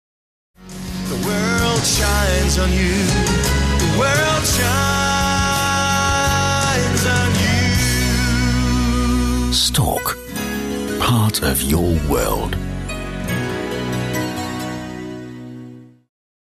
Werbung Englisch (DE)